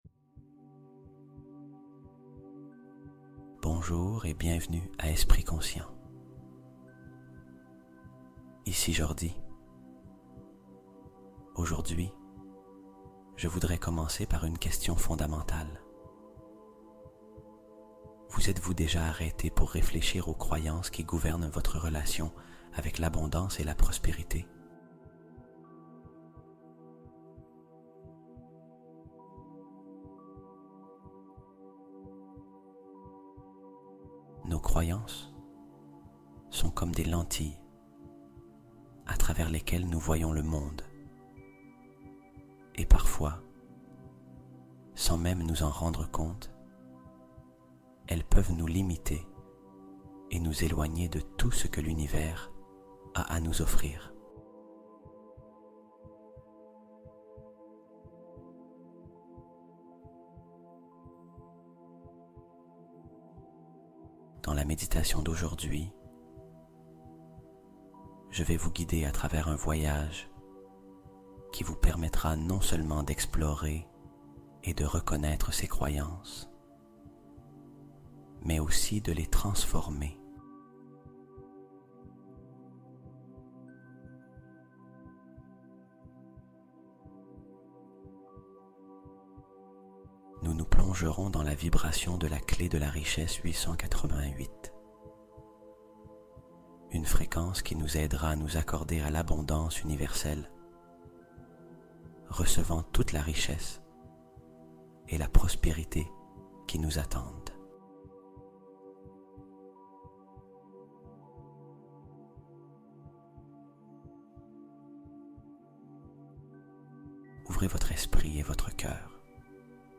Abondance Illimitée : Fréquence 888 Hz pour s'ouvrir à la richesse de vie